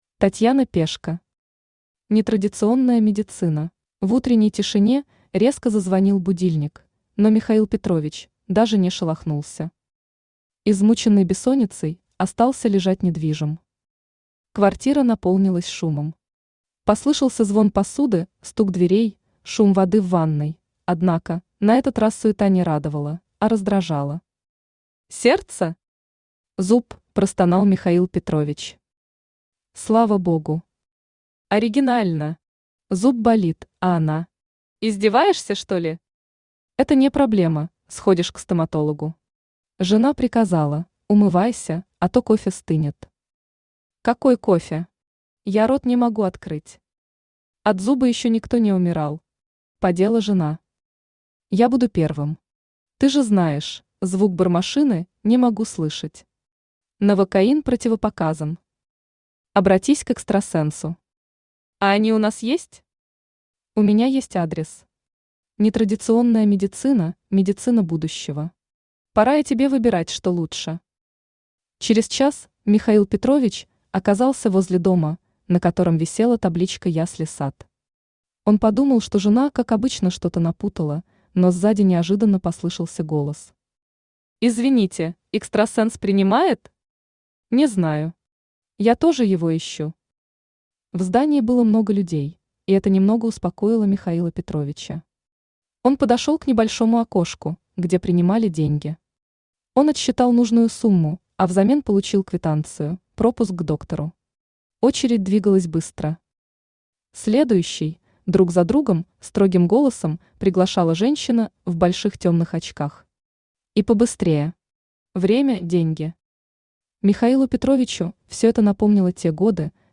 Aудиокнига Нетрадиционная медицина Автор Татьяна Пешко Читает аудиокнигу Авточтец ЛитРес.